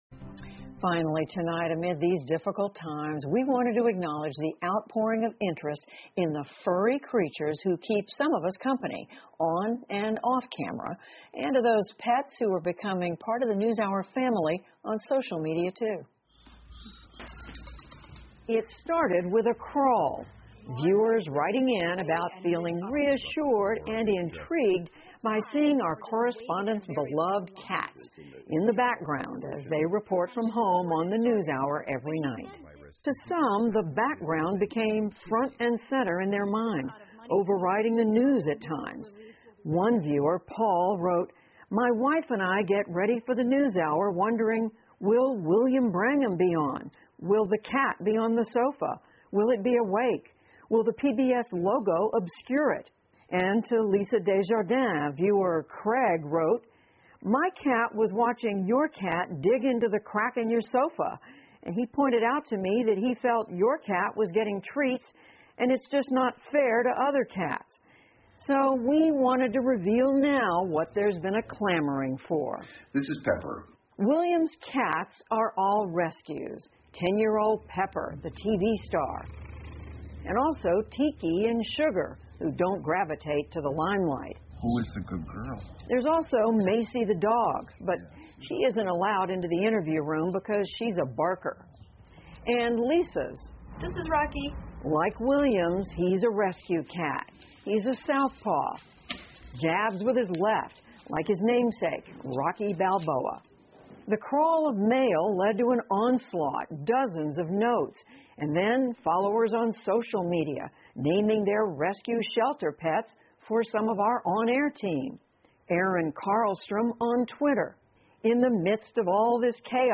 在线英语听力室PBS高端访谈:的听力文件下载,本节目提供PBS高端访谈娱乐系列相关资料,内容包括访谈音频和文本字幕。